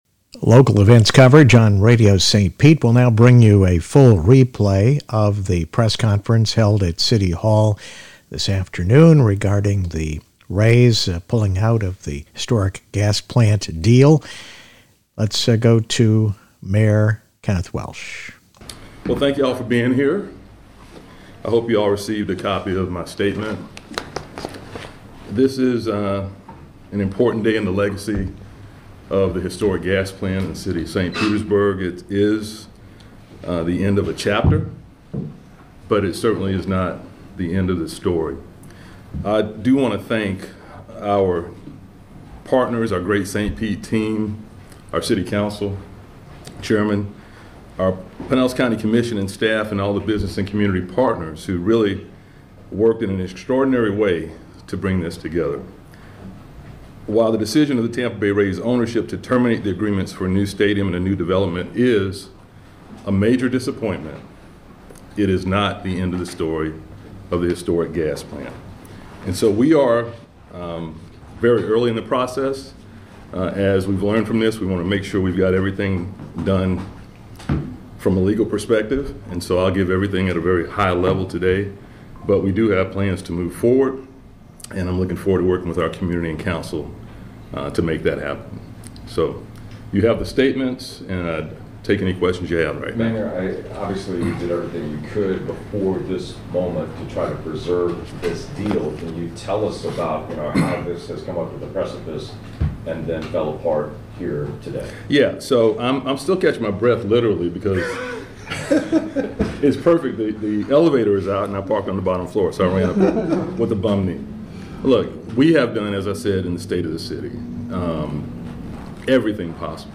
City Press Conference following Rays announcement 3-13-25